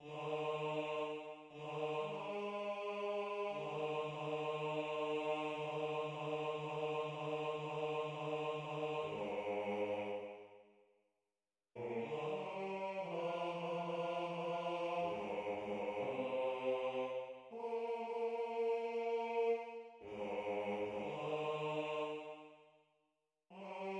{ \new Staff \with {midiInstrument = #"choir aahs"}{\clef bass \tempo 4 = 120 \key ees \major \numericTimeSignature \time 2/2 ees2 r4 ees\time 4/2 aes2. ees4 ees2. ees4 \time 3/2 ees4 ees ees ees ees ees \time 4/2 aes,2 r2 r4 r8 bes,8 ees8 g4.